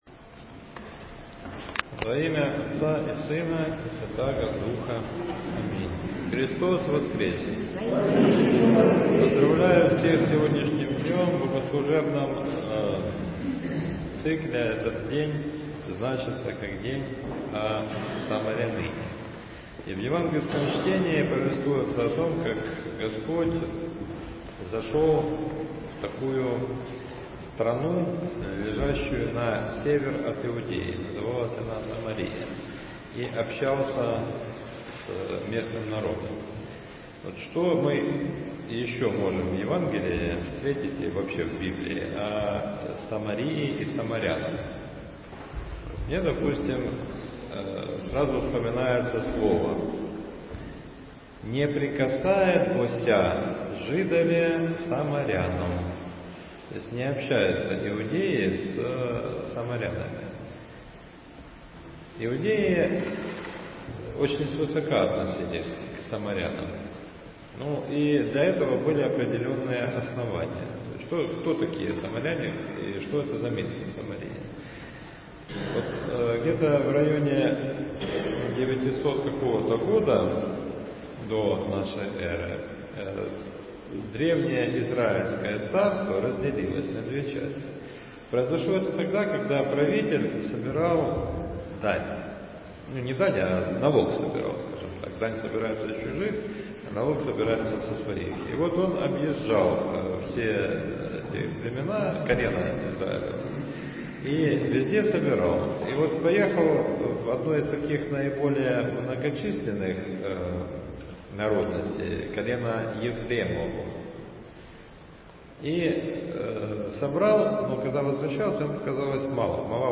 Проповедь в пятую неделю по Пасхе — Спасо-Преображенский мужской монастырь
В воскресение, 18 мая, в пятую неделю по Пасхе о самаряныне на Божественной Литургии читался отрывок из Евангелия от Иоана (4: 5-42).